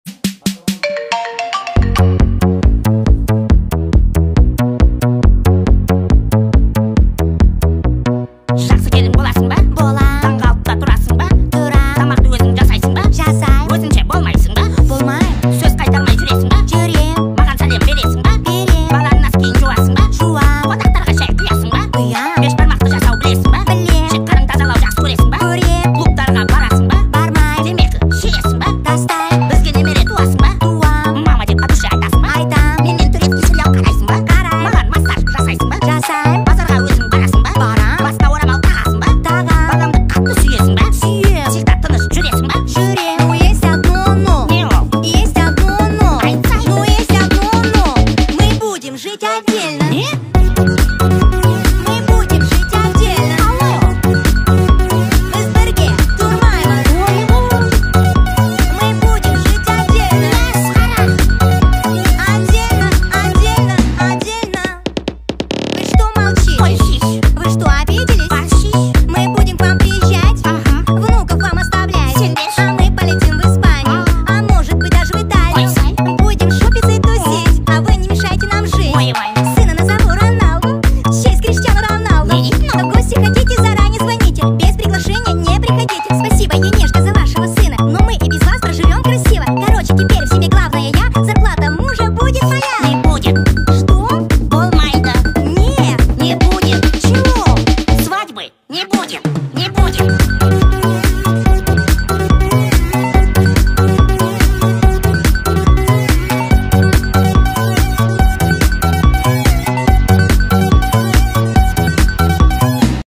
speed up remix